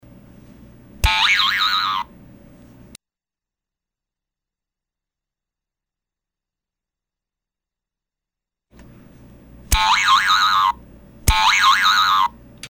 BONG 2